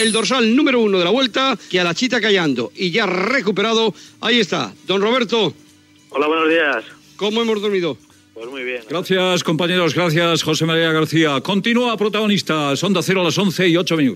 Luis del Olmo talla la connexió amb José María García.
Info-entreteniment